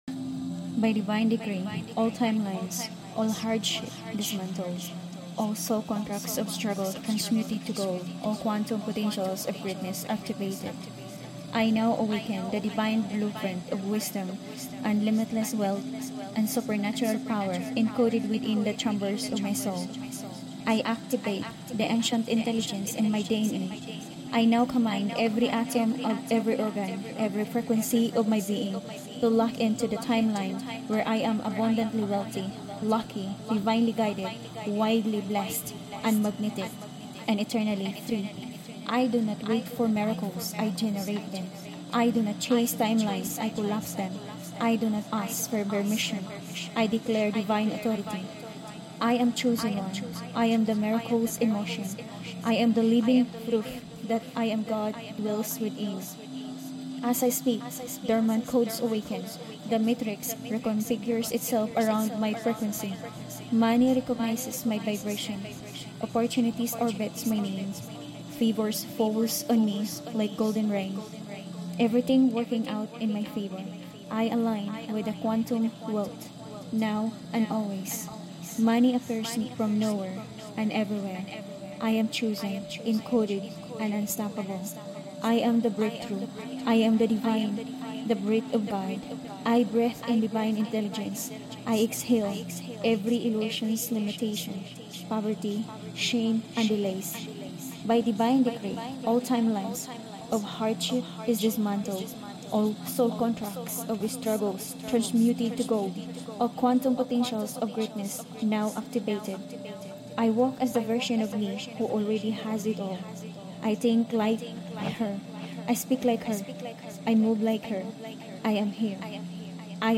🗝 This is more than a rampage this is a quantum recoding. infused with ancient divine technology, and channelled by the original voice of a 5D Powerful Manifestors Academy coach this Quantum Miracle Rampage is not just motivational.